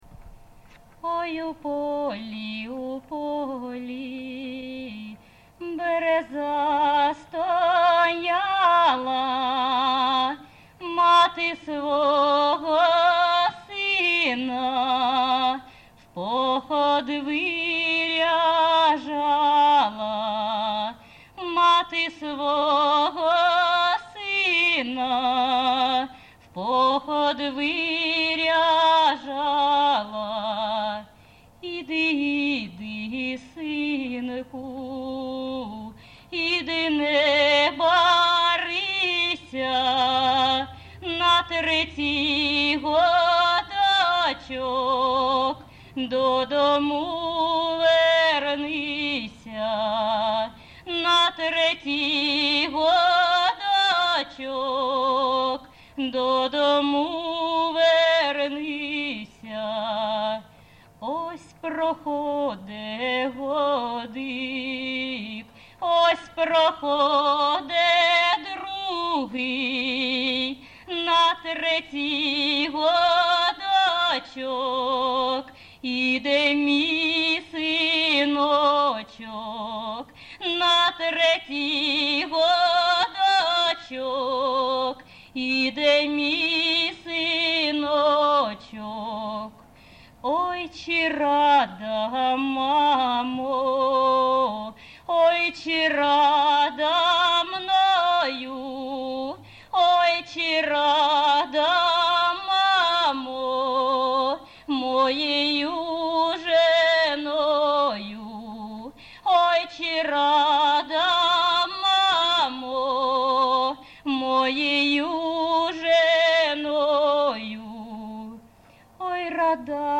Виконавиця співає не в традиційній, а в сценічній манері